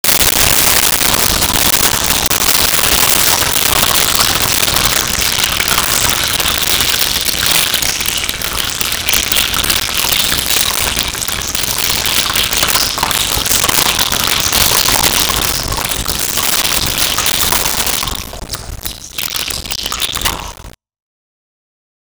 Cave With Water Drip
Cave With Water Drip.wav